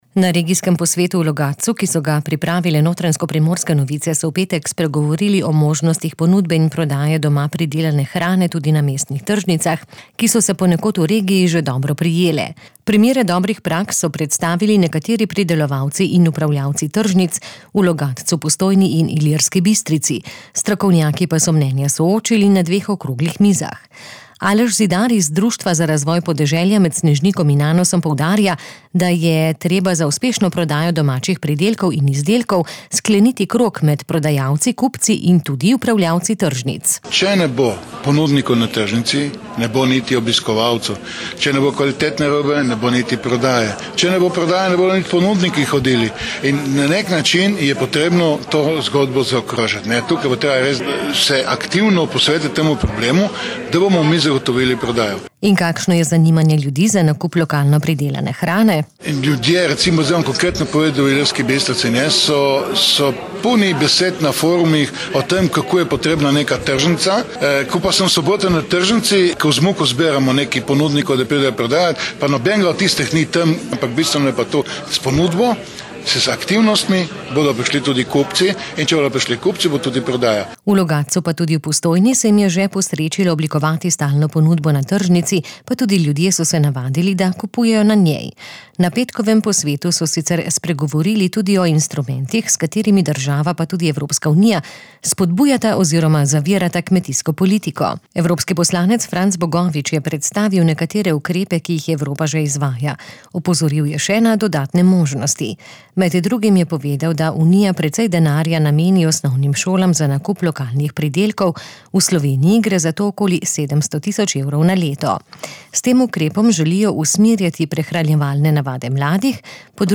Na petkovem posvetu so spregovorili tudi o instrumentih, s katerimi država pa tudi Evropska unija spodbujata oziroma zavirata kmetijsko politiko. Evropski poslanec Franc Bogovič je predstavil nekatere ukrep, ki jih Evropa že izvaja, opozoril je še na dodatne možnosti.